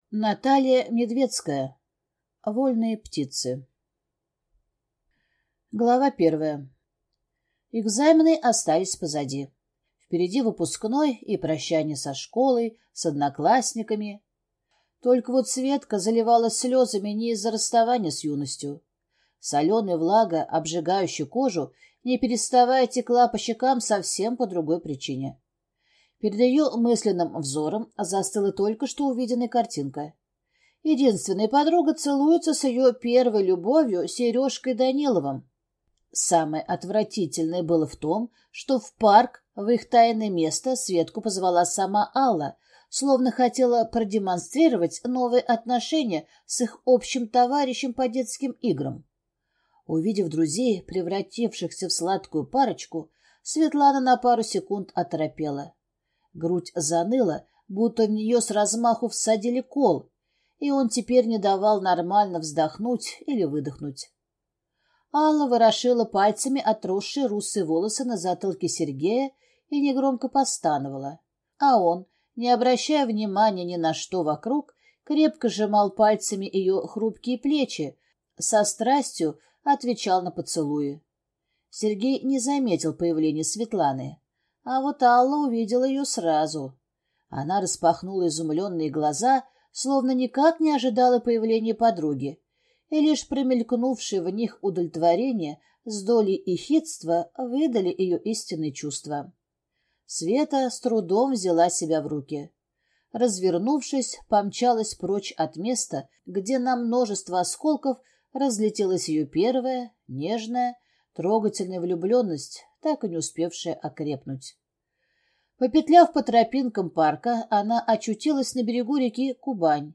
Аудиокнига Вольные птицы | Библиотека аудиокниг